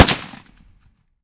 30-30 Lever Action Rifle - Gunshot B 001.wav